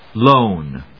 /lóʊn(米国英語), lˈəʊn(英国英語)/